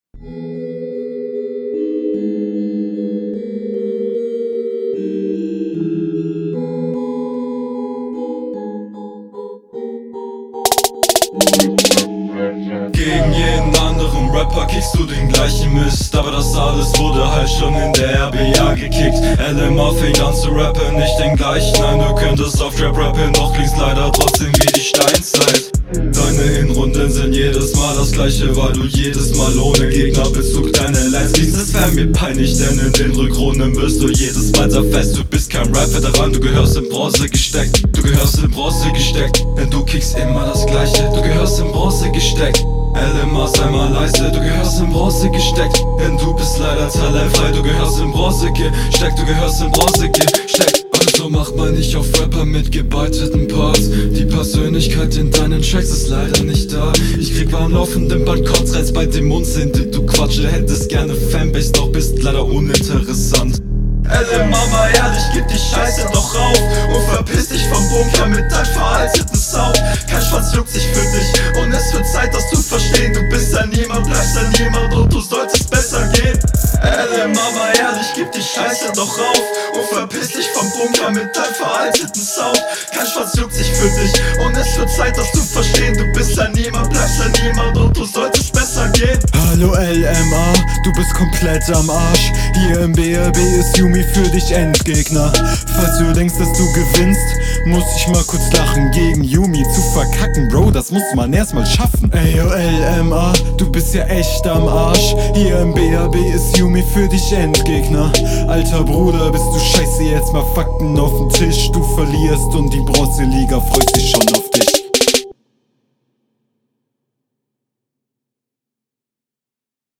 Viiiieeel besser als deine RR, der Beat steht dir um einiges mehr.
Der Beat hat ganz unangenehme höhen im Intro, Im part gehts fit.